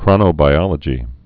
(krŏnō-bī-ŏlə-jē)